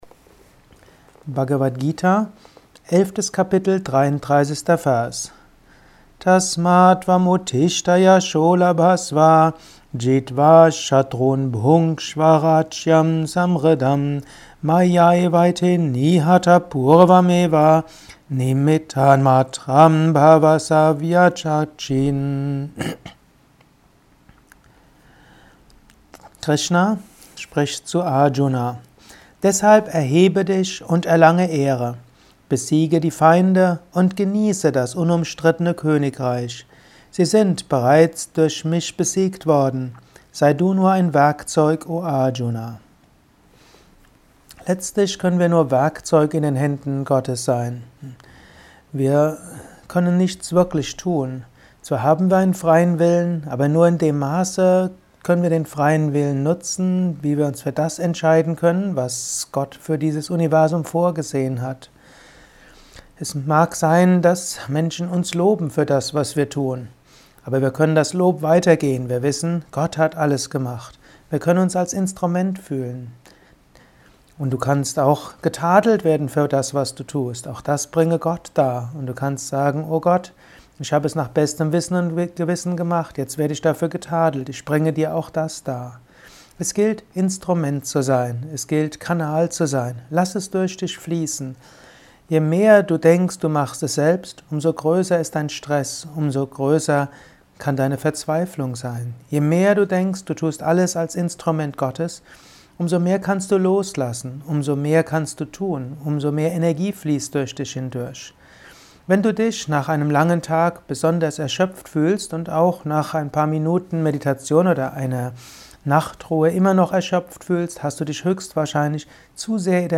Dies ist ein kurzer Kommentar als Inspiration für den heutigen Tag